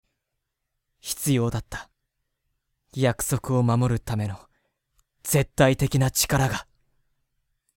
・真面目で勤勉、大人しい少年だった。
【サンプルボイス】